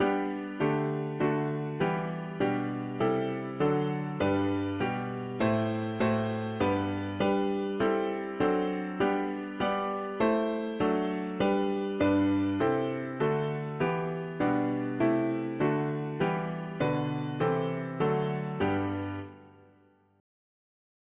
The will to nei… english theist 4part
Key: G major Meter: LM